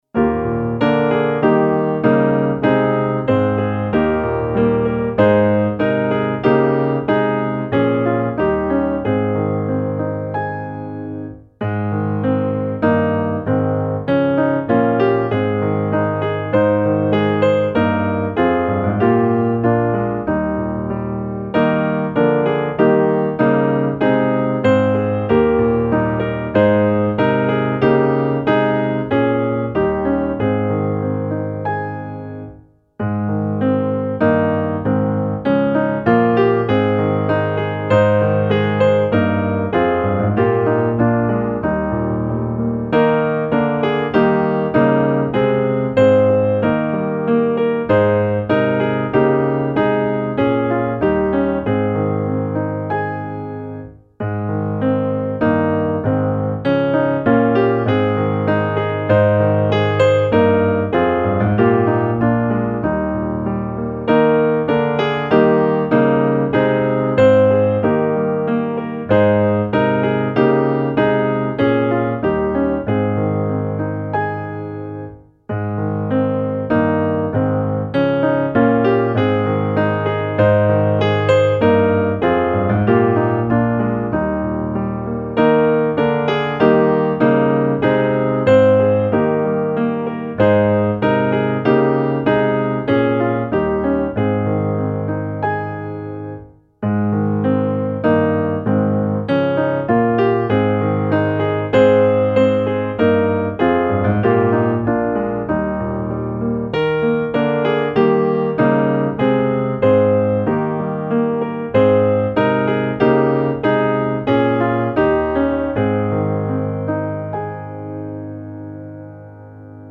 Låt oss glada och i tro - musikbakgrund